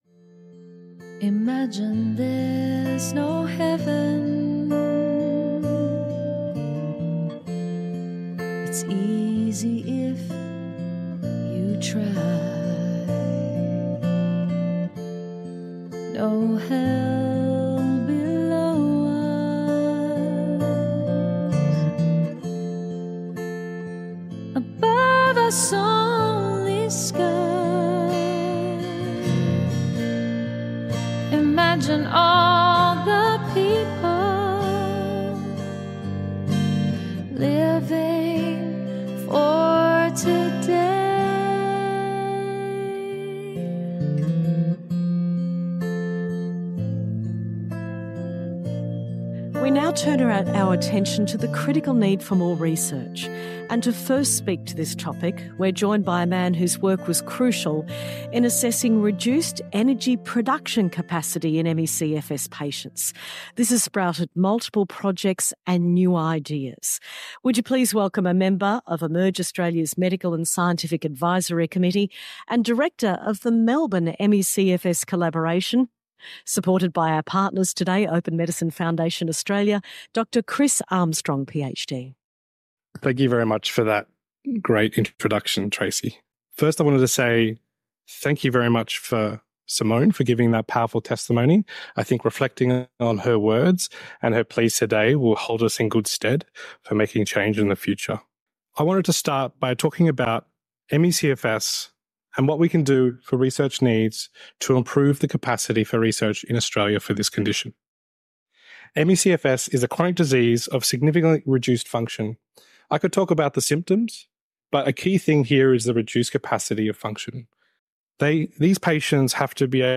rendition of John Lennon’s Imagine to open and close our podcasts